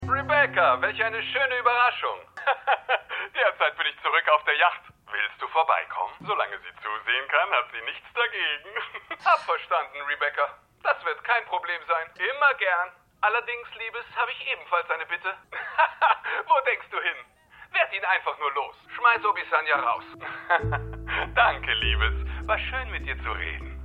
Synchronstudio: FFS Film- & Fernseh-Synchron GmbH